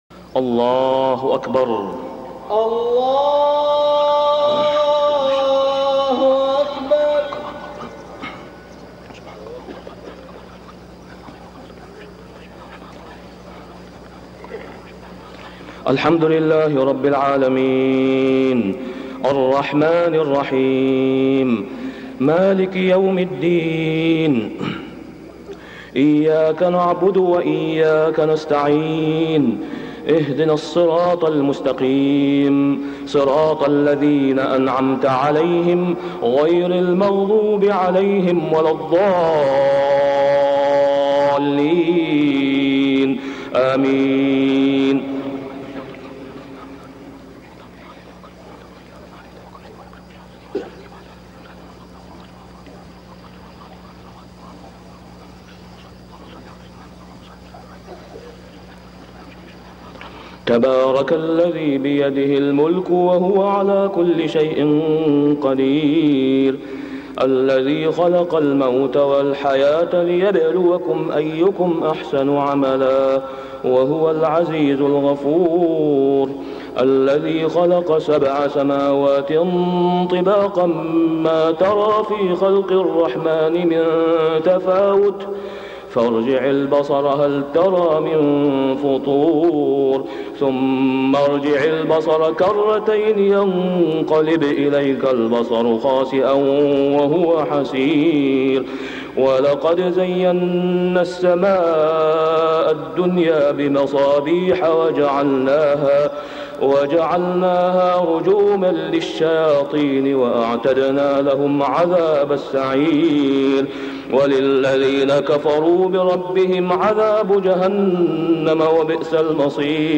صلاة الفجر ذوالقعدة 1421هـ سورة الملك كاملة > 1421 🕋 > الفروض - تلاوات الحرمين